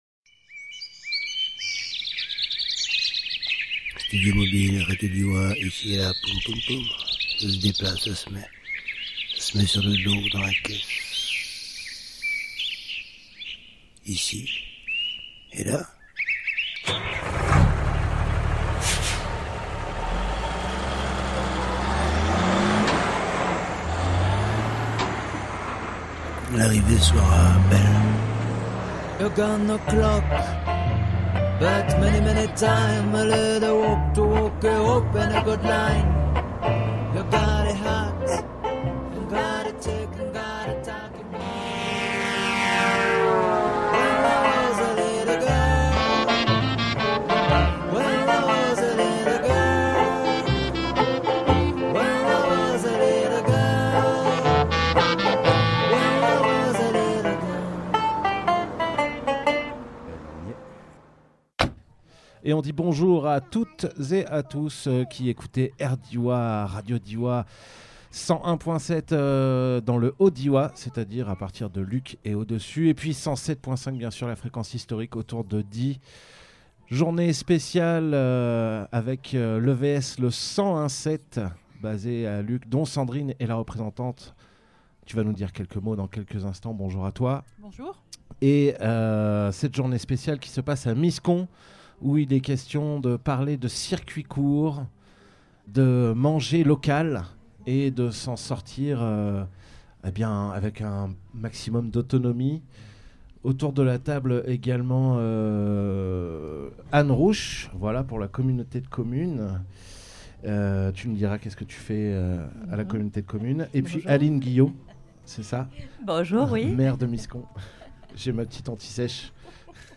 Studio Mobile
Avec aussi une table-ronde radiophonique, l’occasion de parler d’ alimentation et d’autonomie, de production locale et d’économie circulaire, de gestion de l’eau et d’agriculture.
Lieu : Camping municipal de Miscon